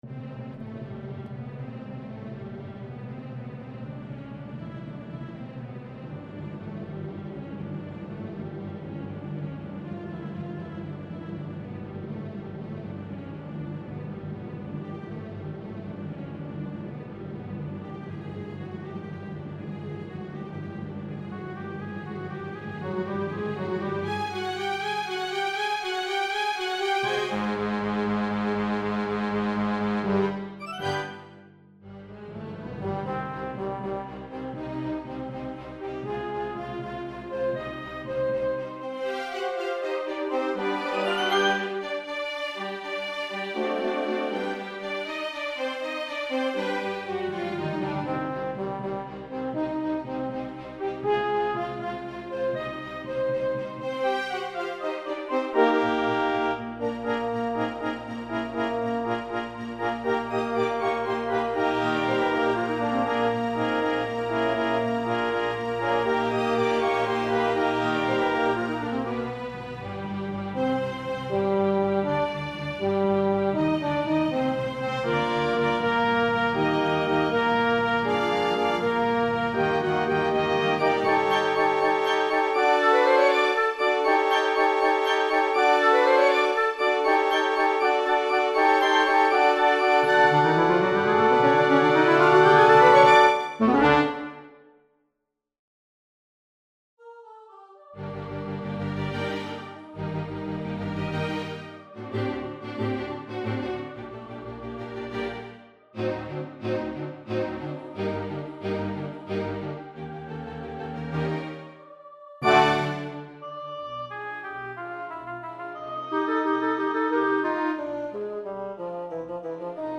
Chorus, Countess, Featherstonhaugh, Girls and Boys